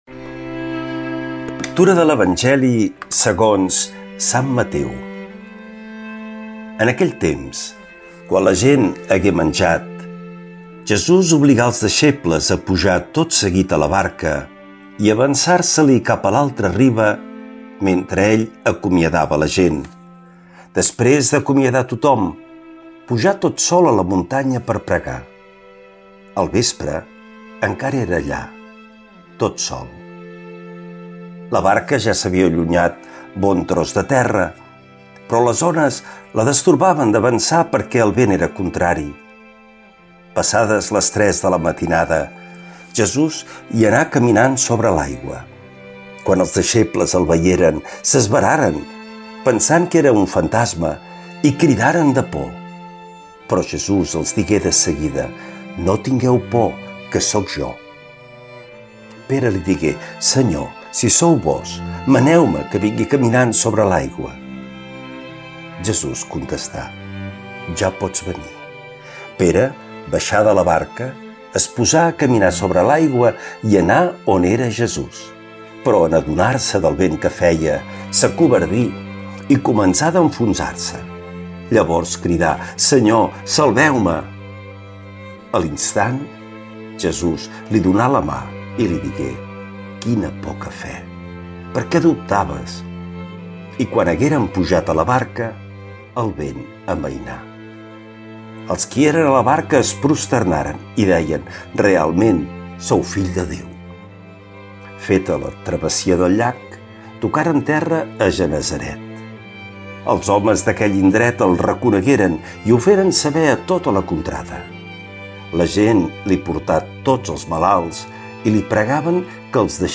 L’Evangeli i el comentari de dimarts 05 d’agost del 2025.
Lectura de l’evangeli segons sant Mateu